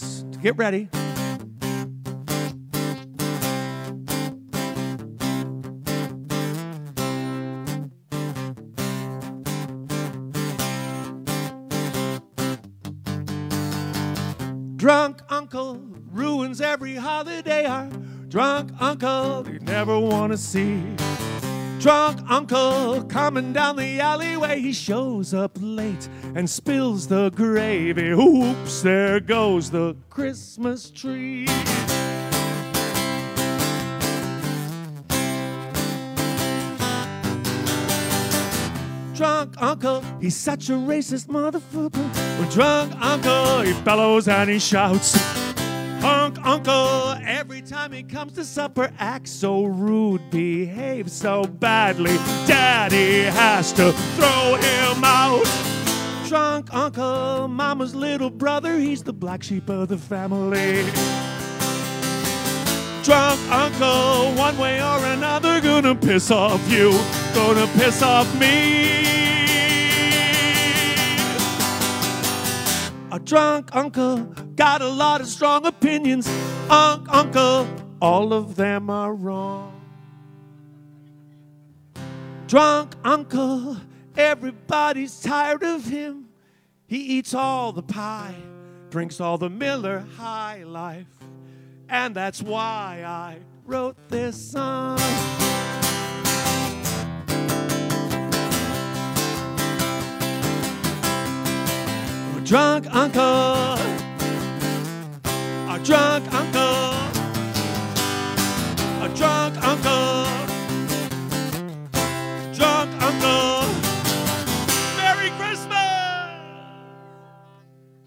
Most are recorded live at his shows